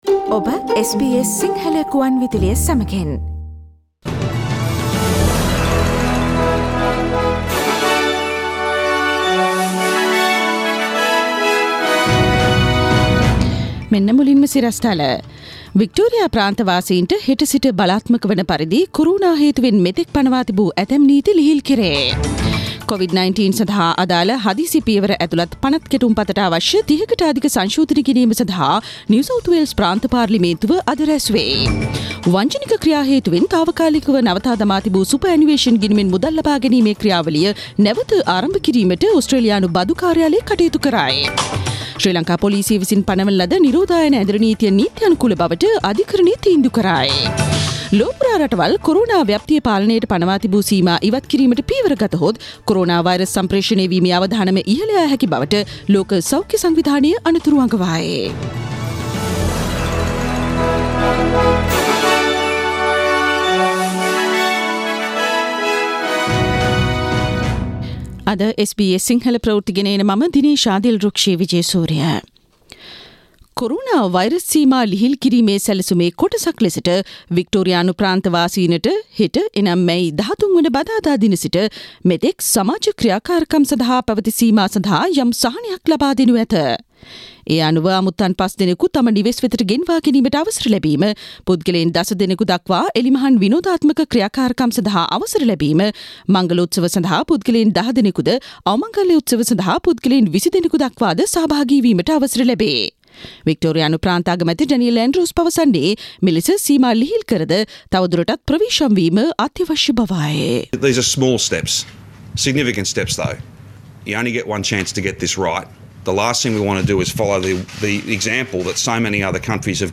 Daily News bulletin of SBS Sinhala Service: 12 May 2020
Today’s news bulletin of SBS Sinhala radio – Tuesday 12 May 2020